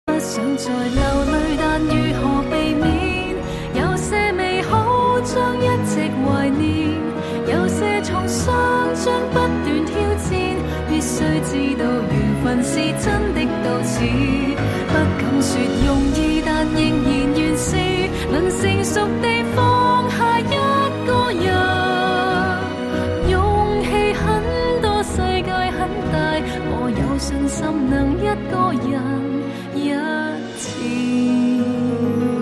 华语歌曲
粤语